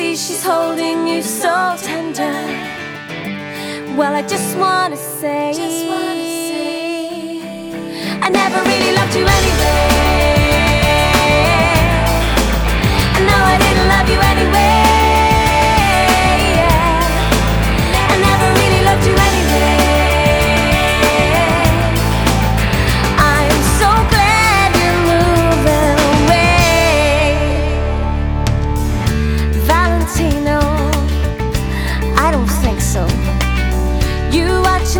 Folk-Rock
Жанр: Поп музыка / Рок / Альтернатива / Фолк